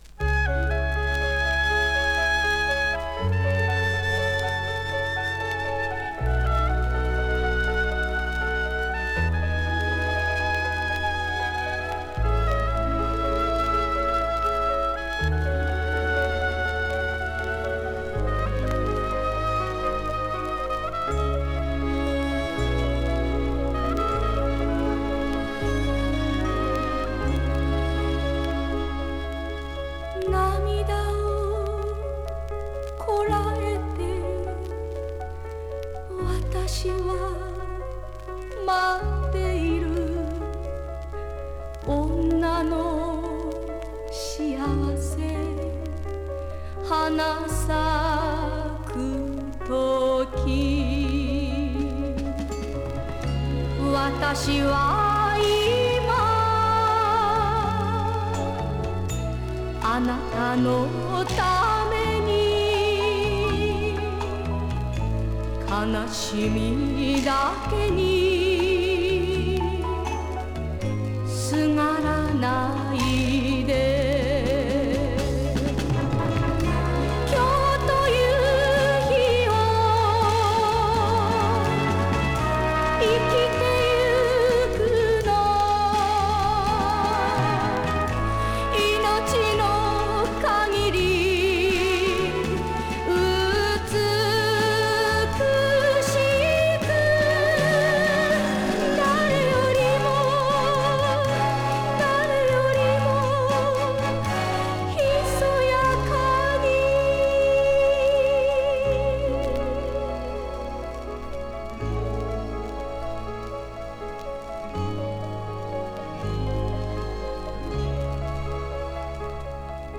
Можно узнать,что за японка здесь поёт (60-70-е)